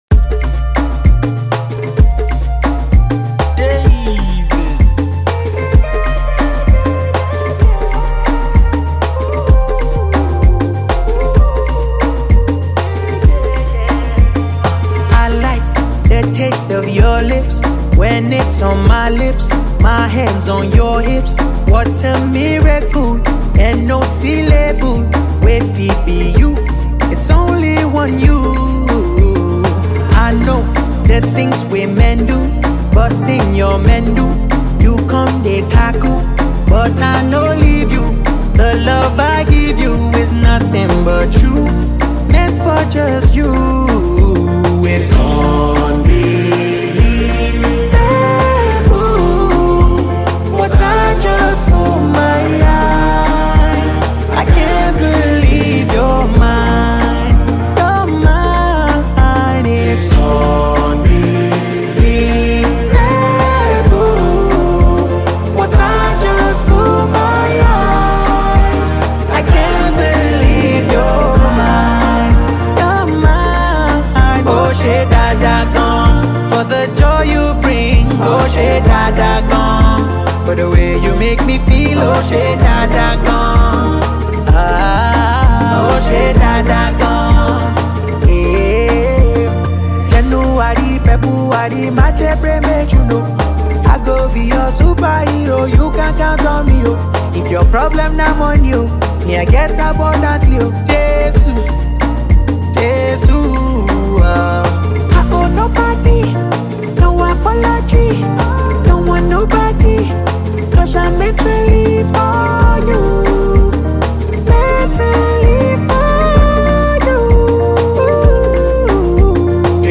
restyled into an rnb